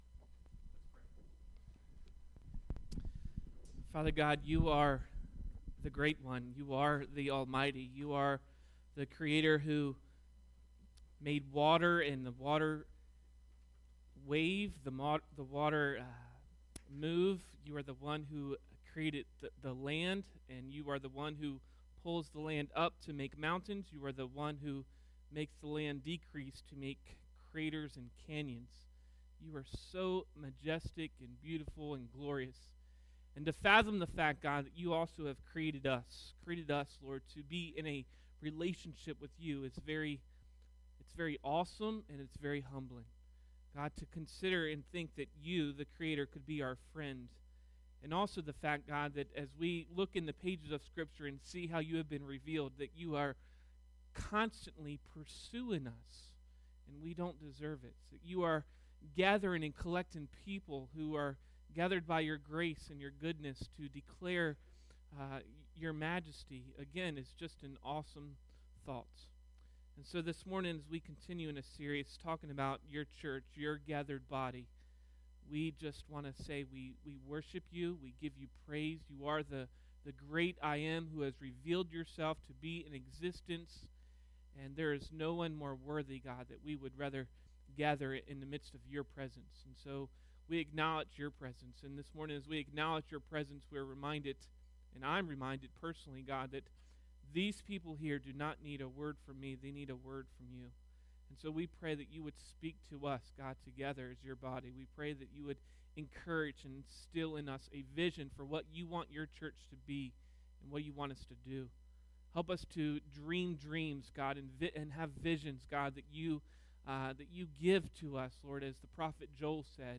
sermon-august-4-2013.mp3